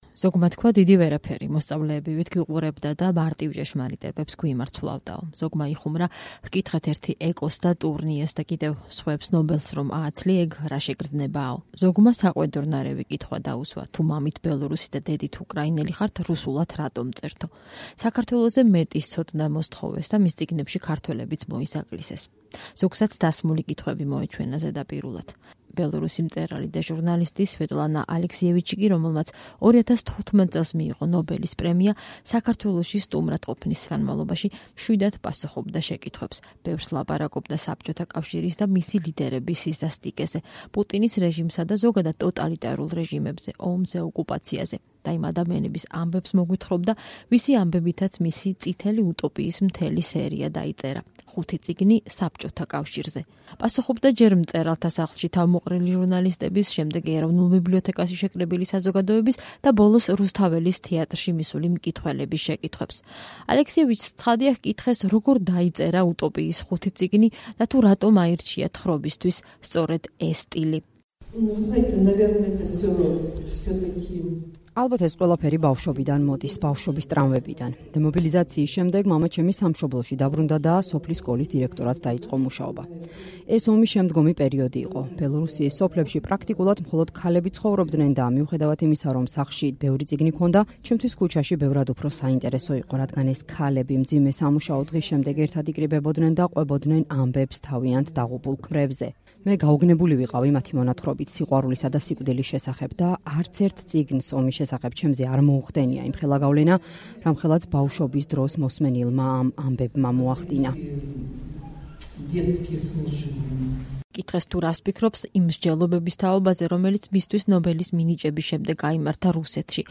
რუსთაველის თეატრის დიდ დარბაზში სვეტლანა ალექსიევიჩს 800-მდე ადამიანი უსმენდა. ნობელის პრემიის ლაურეატი, ბელორუსი მწერალი საქართველოში გამომცემლობების, „ინტელექტისა“ და „არტანუჯის“ მოწვევით ჩამოვიდა.